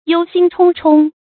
注音：ㄧㄡ ㄒㄧㄣ ㄔㄨㄙ ㄔㄨㄙ
憂心忡忡的讀法